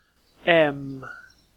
Ääntäminen
Tuntematon aksentti: IPA : /ɛm/